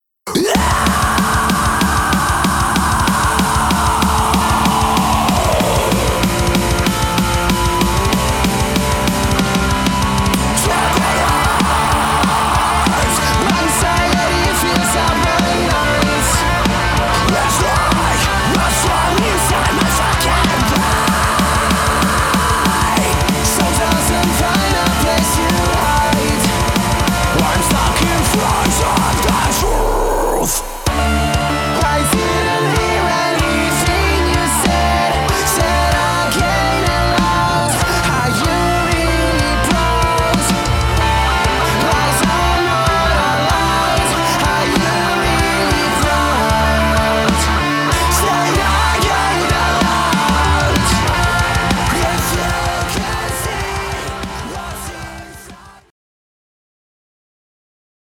Metalcore band